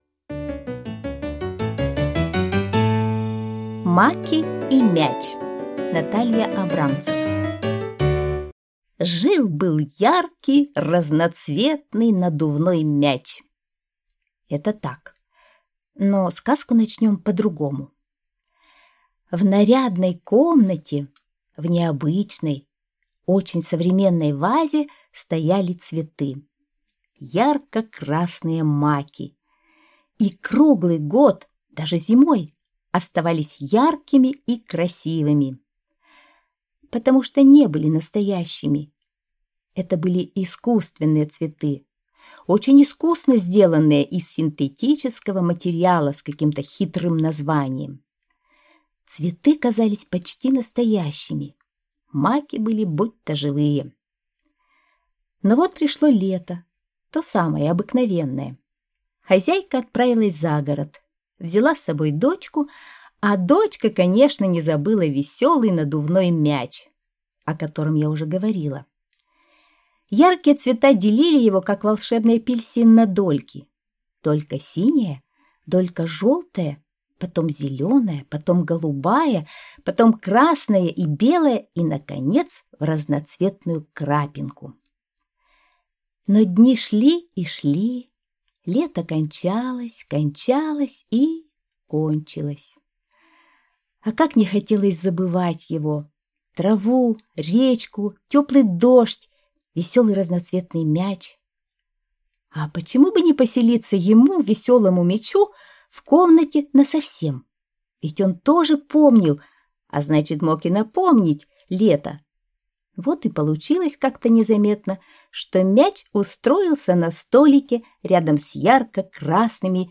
Маки и мяч - аудиосказка Натальи Абрамцевой - слушать онлайн